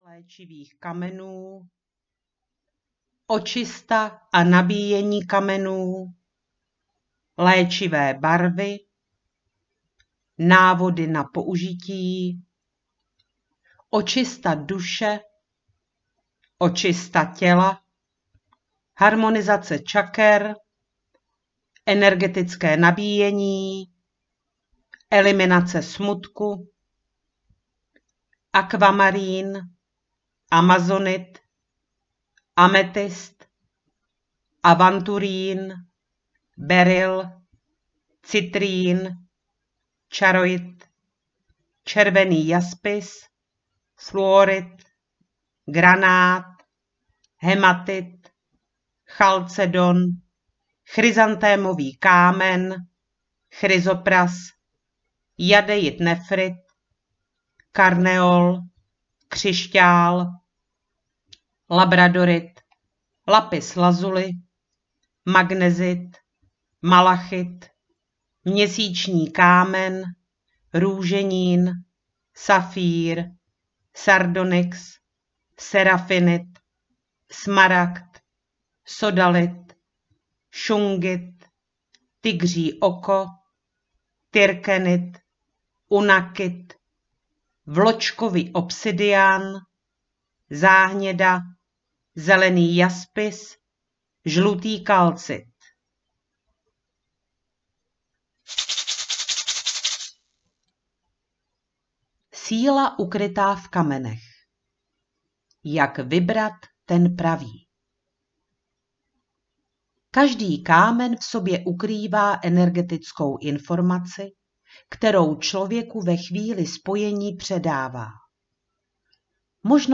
Kameny léčí audiokniha
Ukázka z knihy